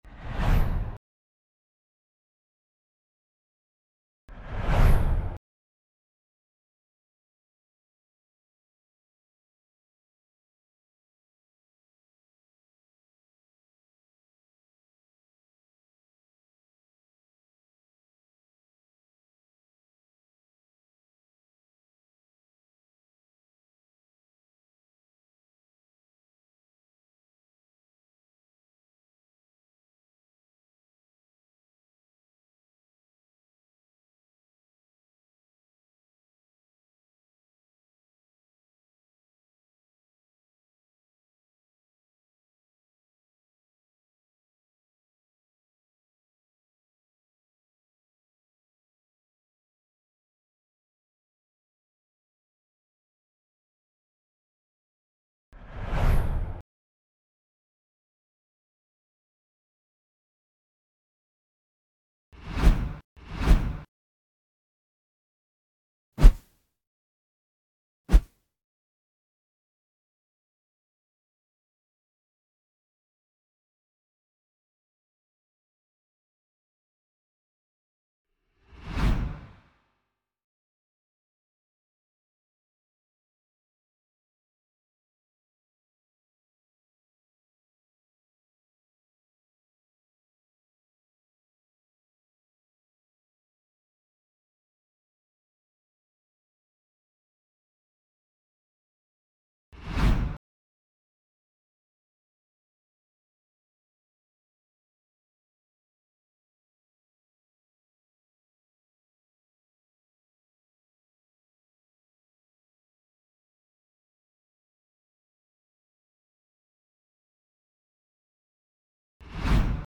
[Voice-over]